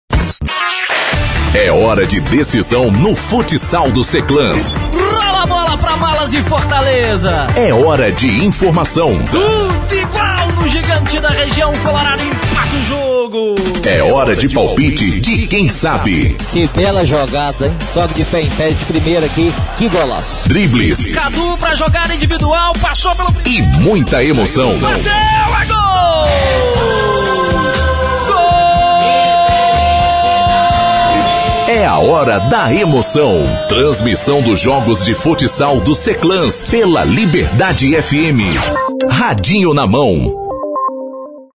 CHAMADA-DE-RÁDIO2.mp3